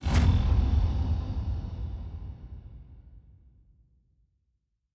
pgs/Assets/Audio/Sci-Fi Sounds/Interface/Complex Interface 3.wav at master
Complex Interface 3.wav